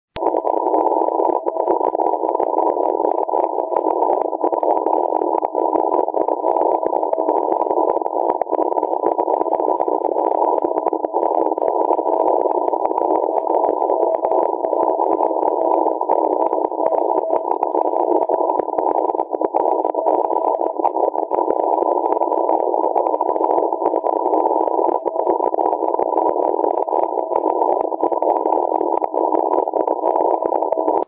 first, a CW CQ EME is made completely inaudible by static crashes.
cq_unfiltered.mp3